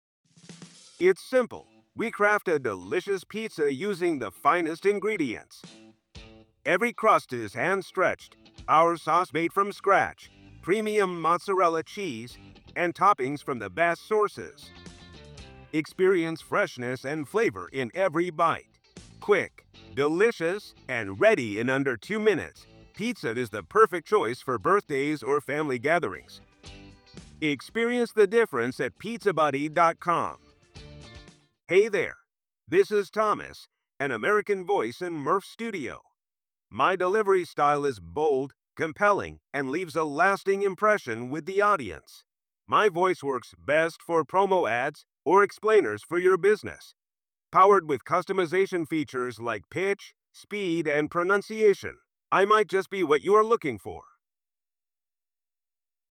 Murf AI example output
Its advanced AI algorithms and deep learning techniques allow it to generate voices that are almost indistinguishable from human speech.